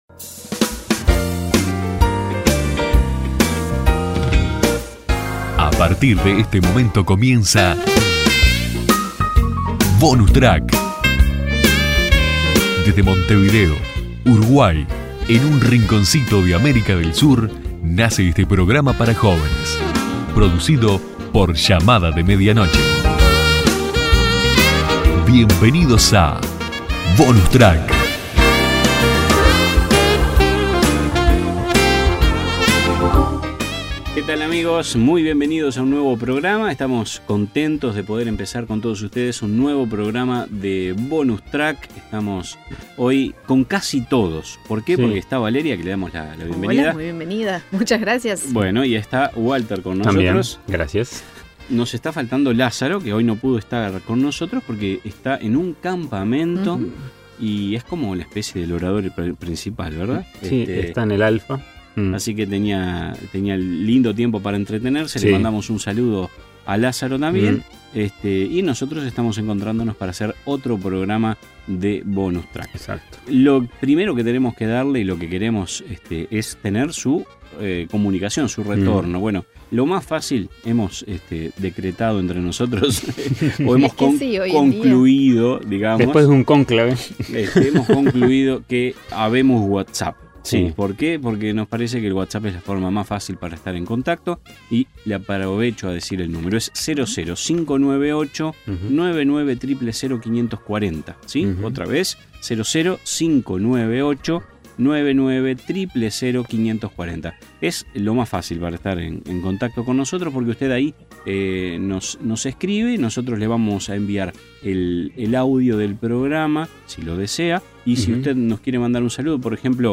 Te invitamos a reflexionar con nosotros y a escuchar una conversación que nos edifica a todos.
Bonus Track, te invita a que te prendas en sintonía durante 28 minutos para compartir un poco de todo: opiniones, invitados, un buen tema de conversación y la buena música, que no debe faltar.